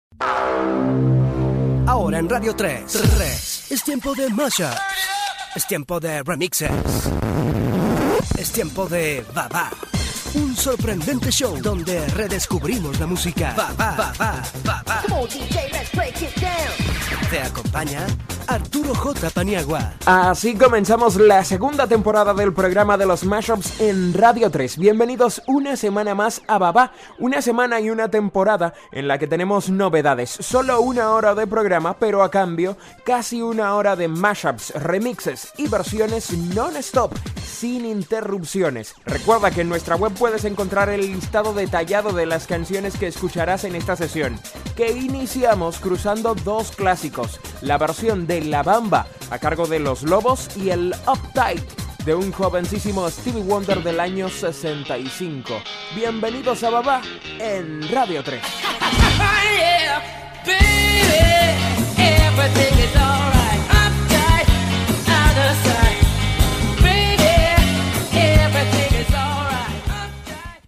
Careta del programa, presentació del primer programa de la segona temporada i presentació del primer tema musical
Musical